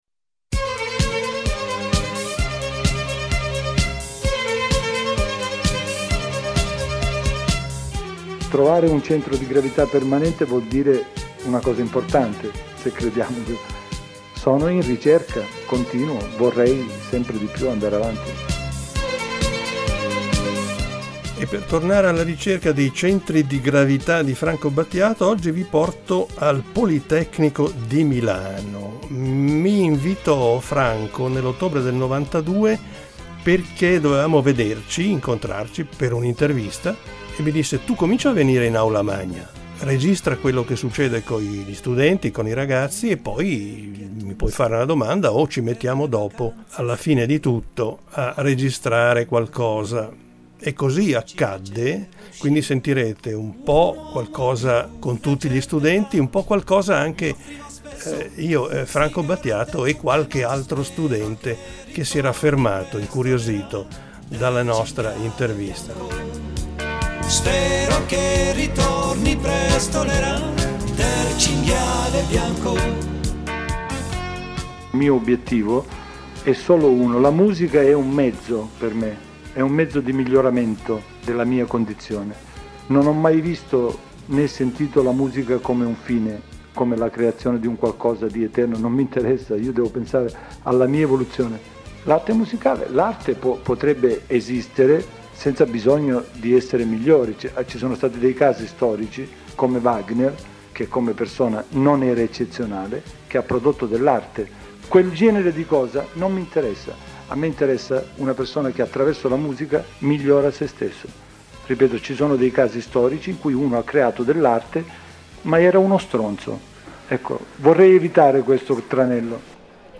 ottobre 1992 al Politecnico di Milano